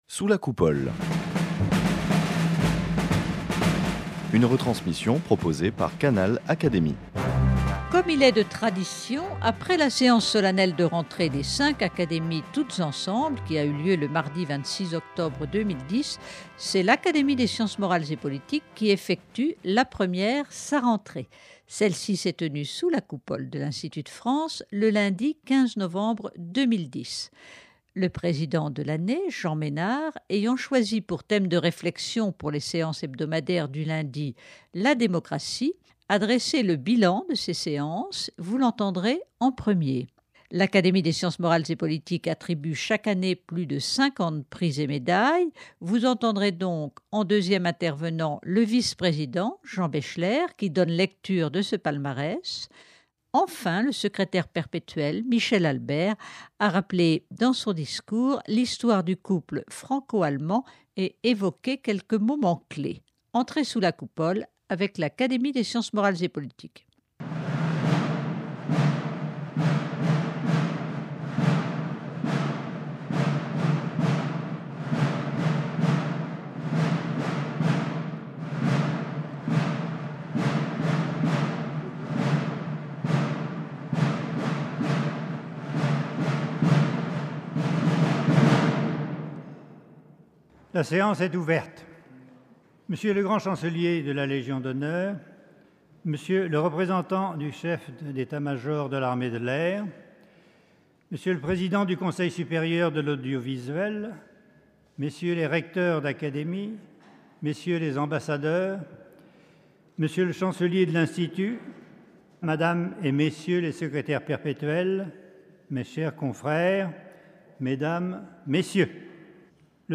Elle s'est tenue sous la Coupole de l'Institut de France le lundi 15 novembre 2010.
Le vice-président Jean Baechler a donné lecture de ce palmarès et les invités, sous la Coupole, n'ont pas manqué de saluer de leurs applaudissements, les lauréats.